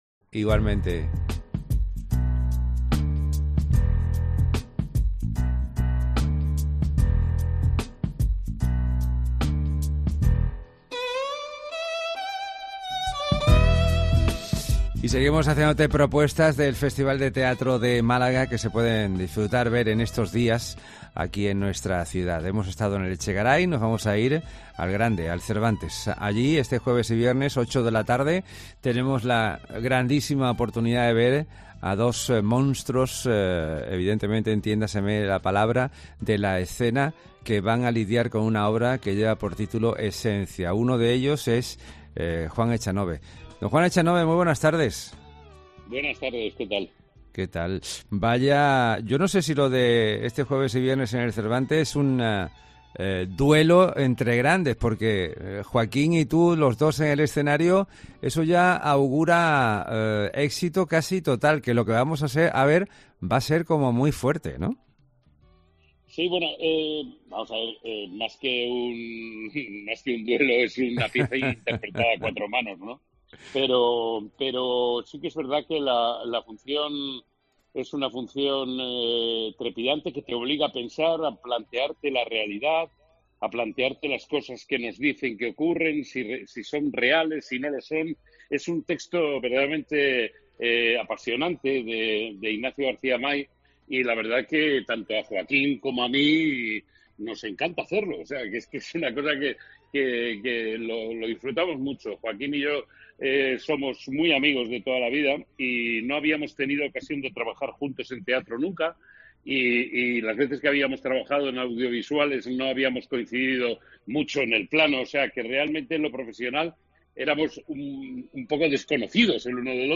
Entrevista a Juan Echanove